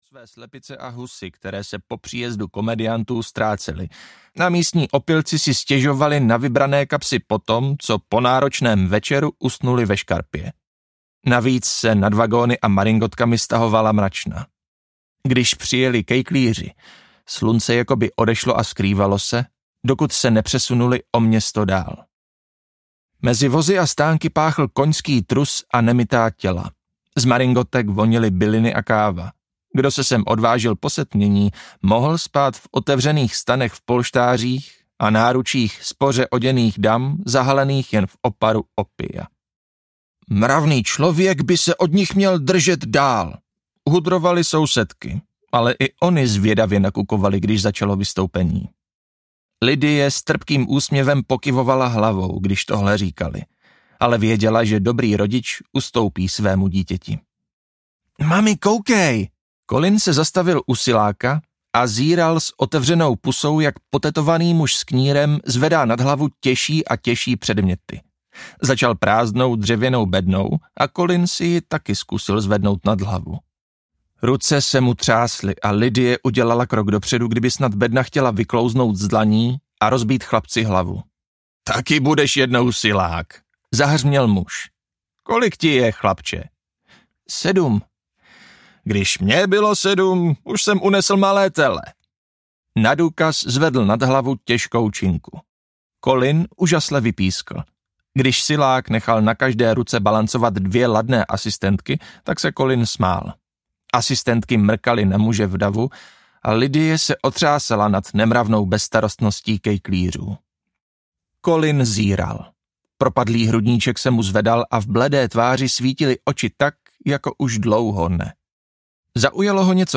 Smečka audiokniha
Ukázka z knihy